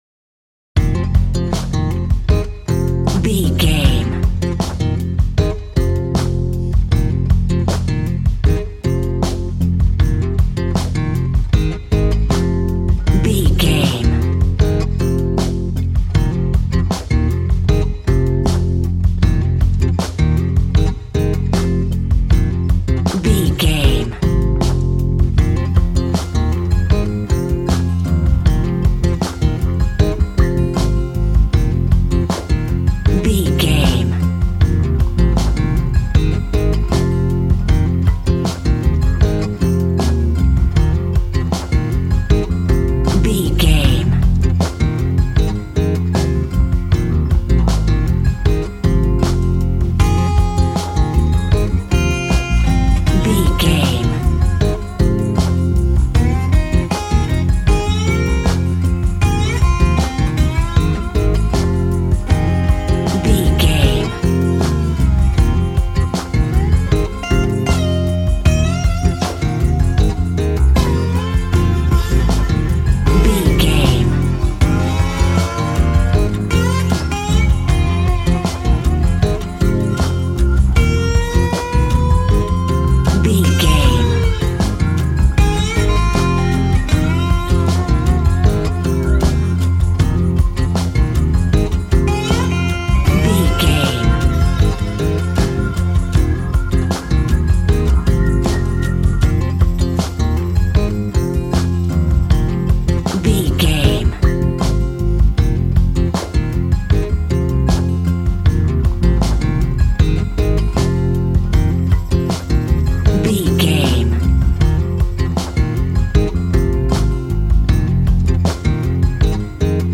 Aeolian/Minor
romantic
happy
acoustic guitar
bass guitar
drums